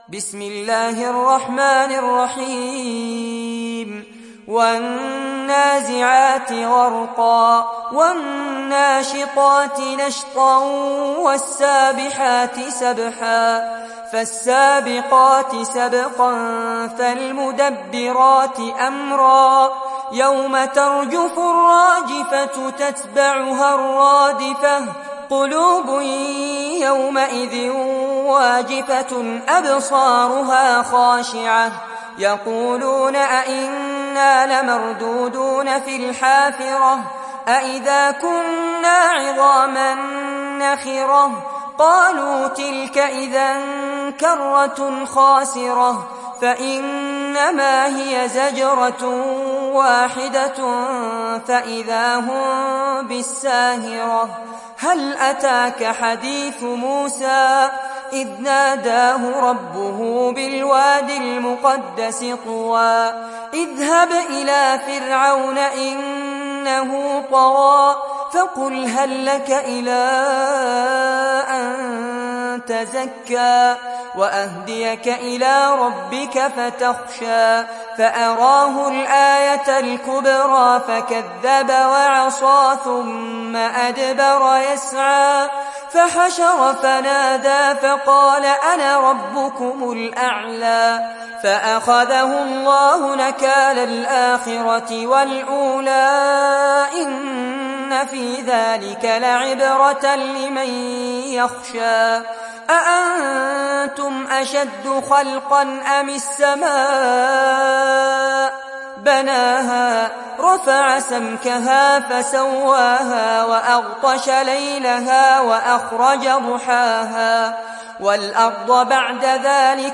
تحميل سورة النازعات mp3 بصوت فارس عباد برواية حفص عن عاصم, تحميل استماع القرآن الكريم على الجوال mp3 كاملا بروابط مباشرة وسريعة